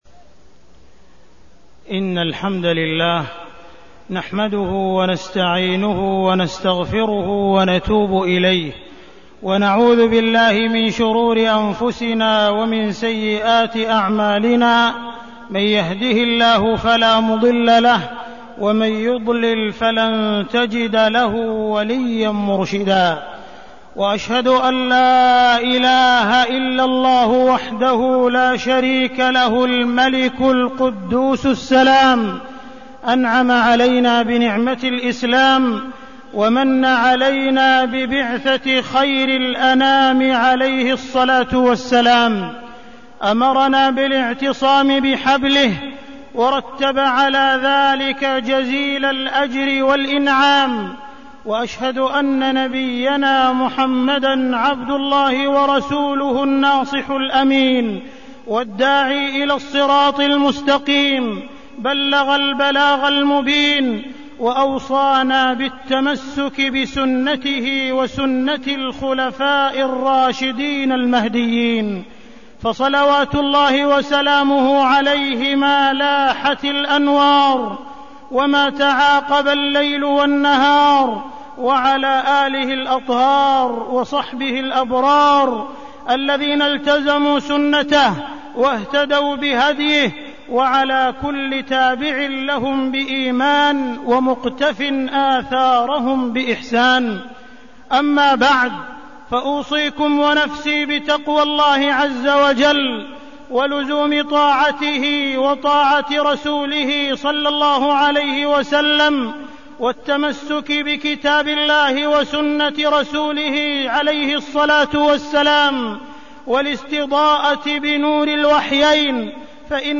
تاريخ النشر ٧ رجب ١٤١٨ هـ المكان: المسجد الحرام الشيخ: معالي الشيخ أ.د. عبدالرحمن بن عبدالعزيز السديس معالي الشيخ أ.د. عبدالرحمن بن عبدالعزيز السديس الولاء لهذا الدين